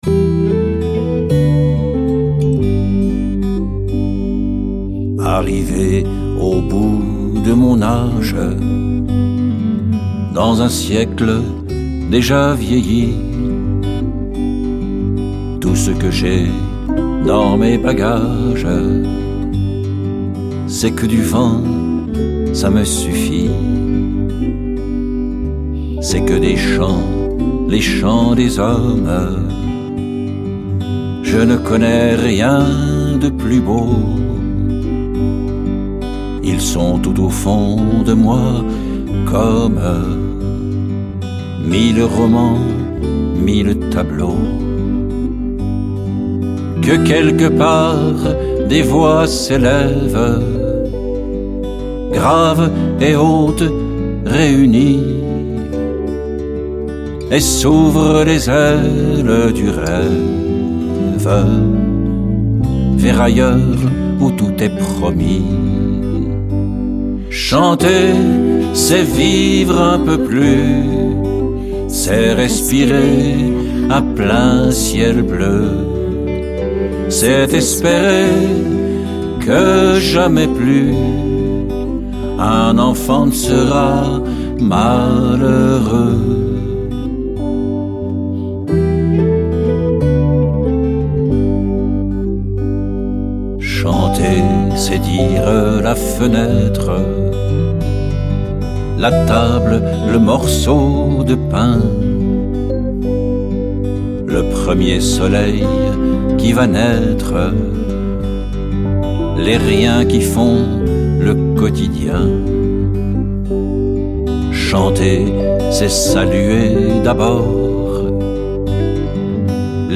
claviers, instrument midi
Guitares
Accordéon, accordina
Contrebasse, basse électrique
Batterie, percussions